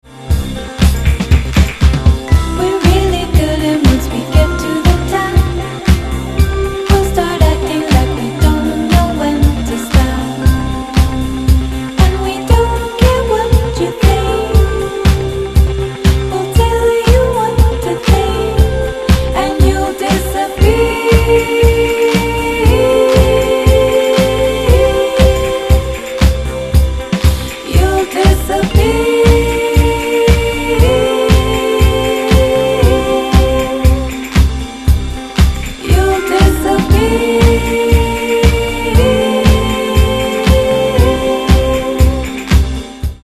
superhot psychedelic funk from outta space